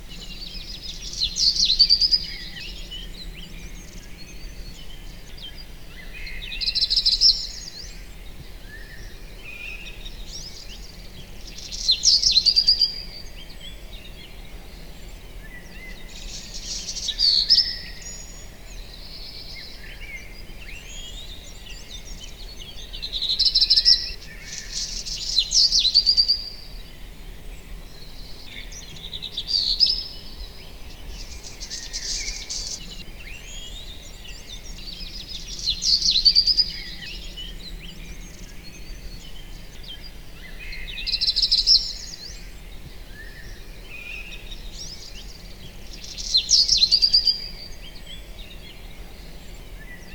birds 1